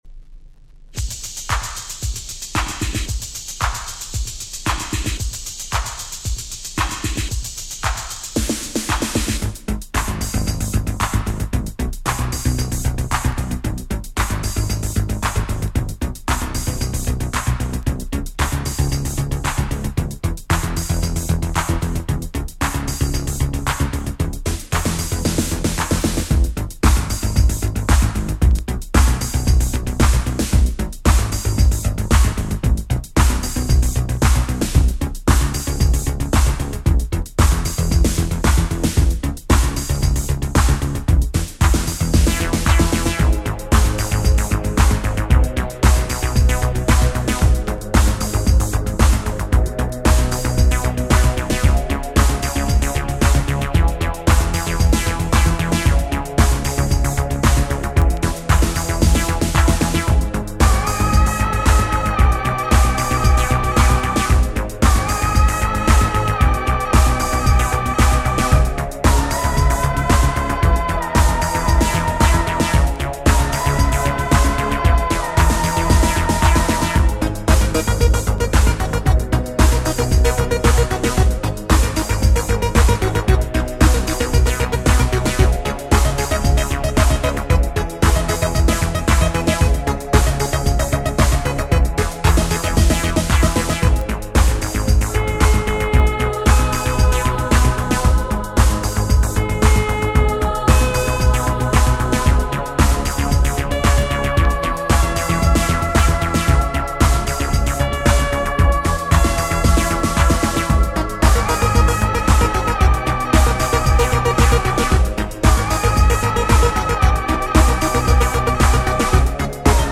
Género: Retro.